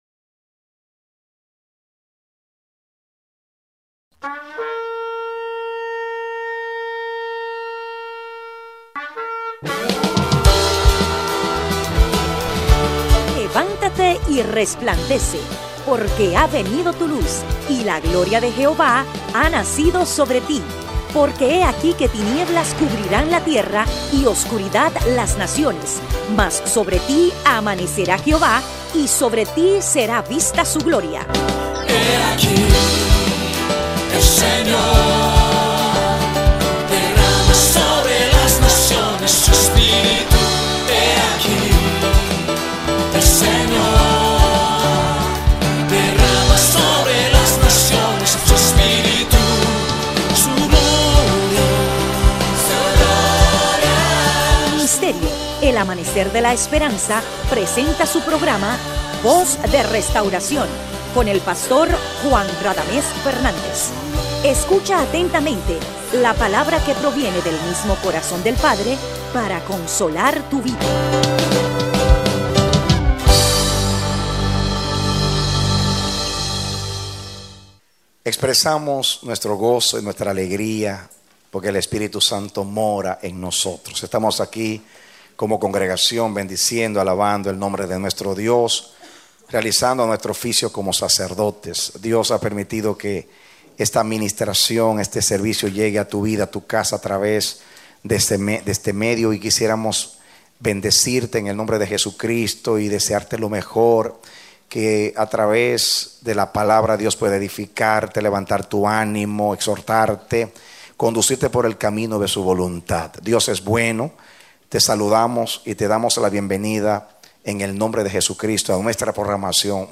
Mensaje: “Nuestro Dios Uno es # 2”